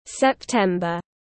Tháng 9 tiếng anh gọi là september, phiên âm tiếng anh đọc là /sepˈtem.bər/
September /sepˈtem.bər/